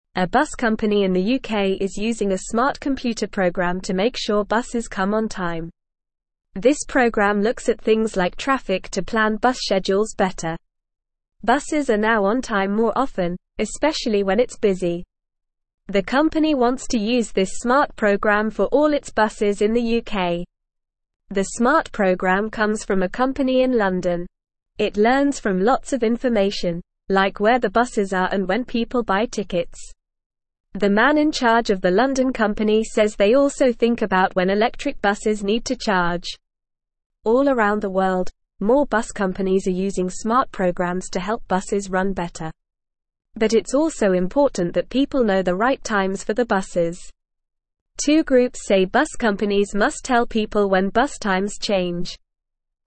Normal
English-Newsroom-Beginner-NORMAL-Reading-Smart-Program-Helps-UK-Buses-Arrive-on-Time.mp3